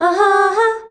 AHAAA   F.wav